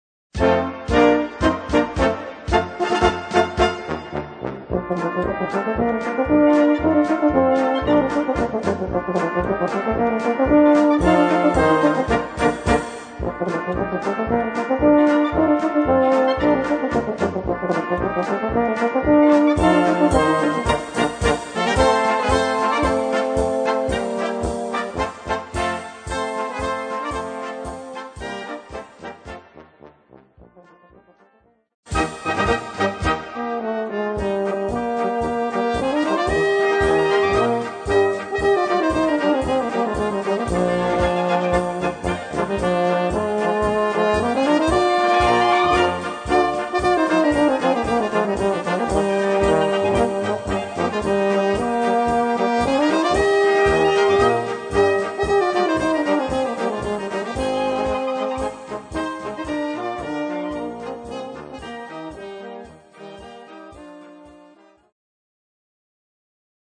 Solo für Flügelhorn und Tenorhorn
Blasorchester